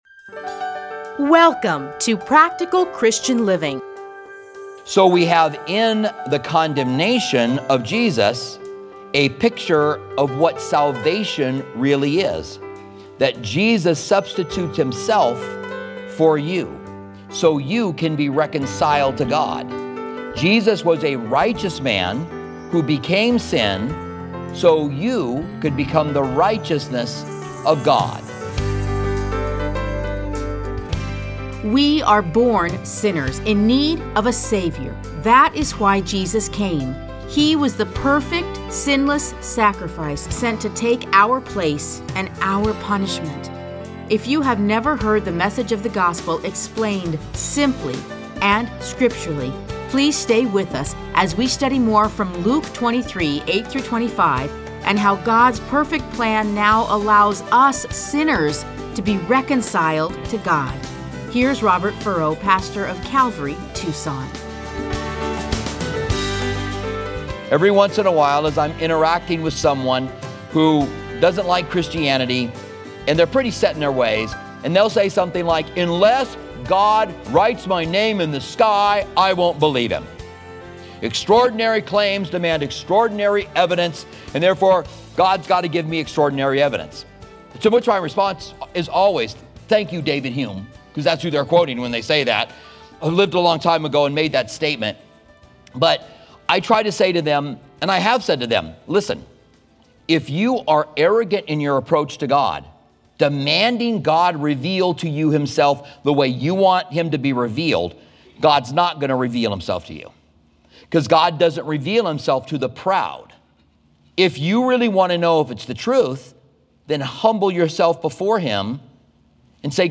Listen to a teaching from Luke 23:26-31.